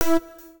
UIClick_Alien Interface 01.wav